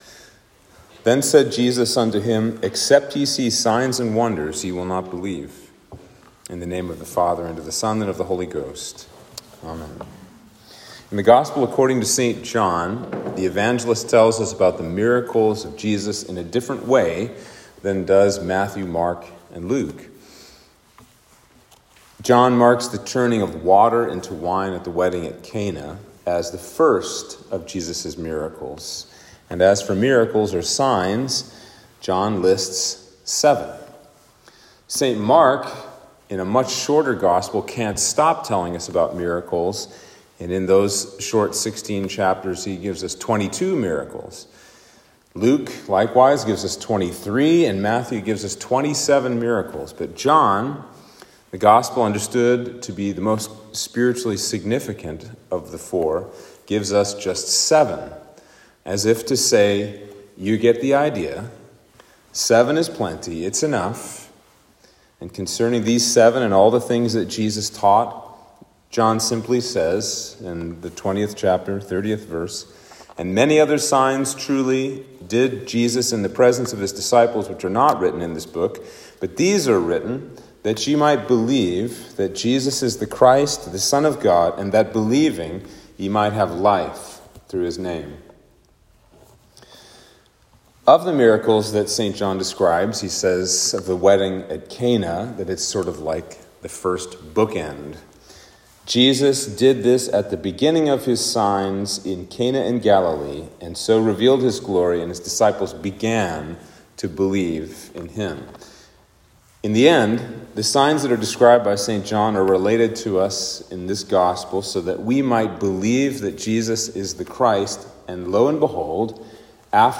Sermon for Trinity 21